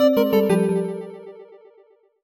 jingle_chime_22_negative.wav